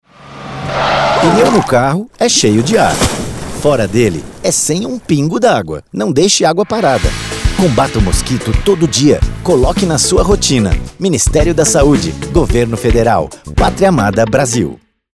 Spot - Mosquito Pneu